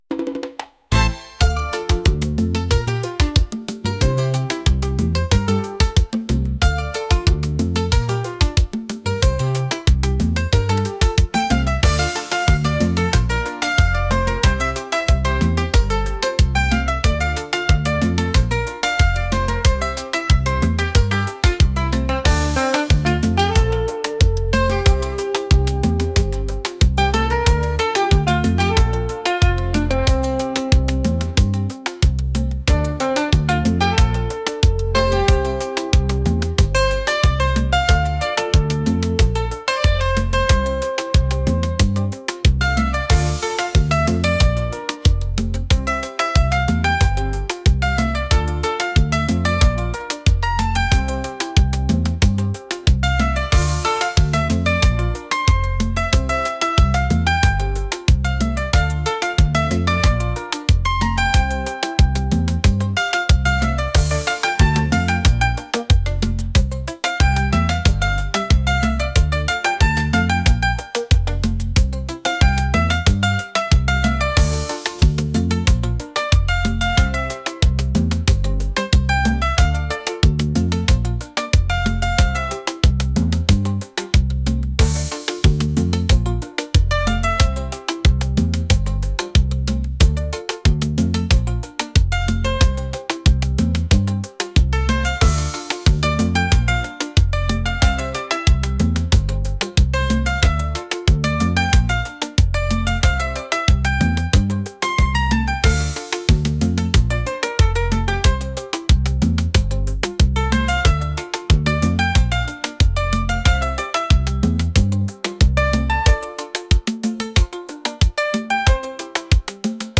rhythmic